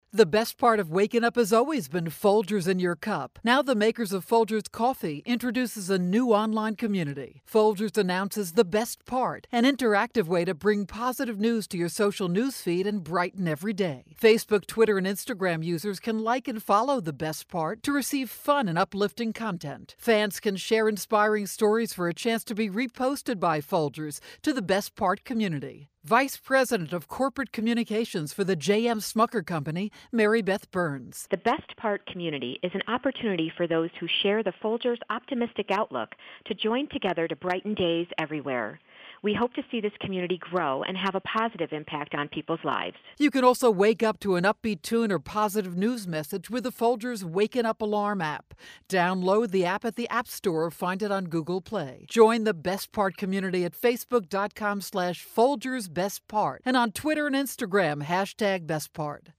October 7, 2013Posted in: Audio News Release